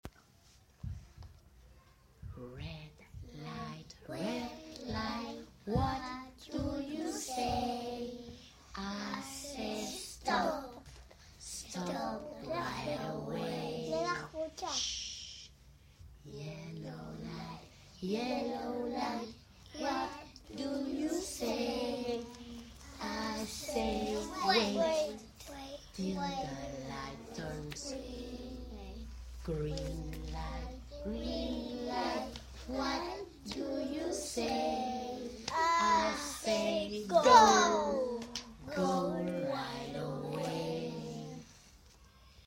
Songs